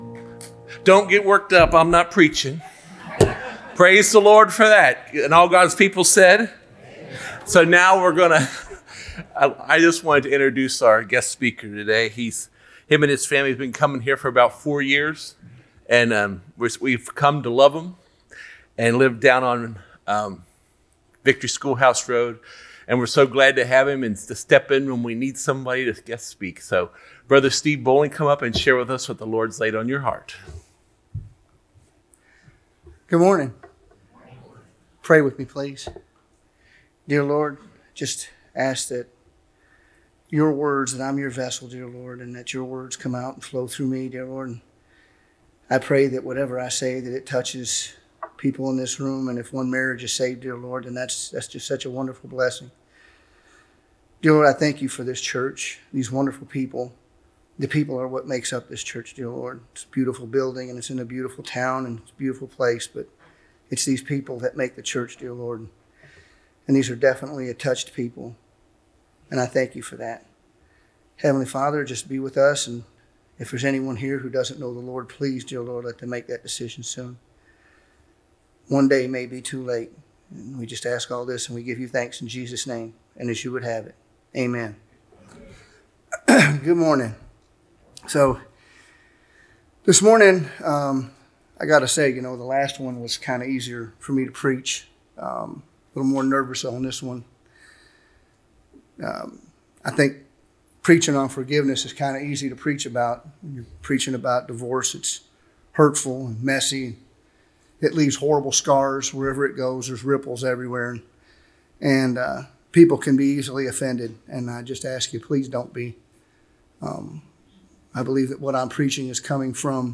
Sunday Morning Services | Belleview Baptist Church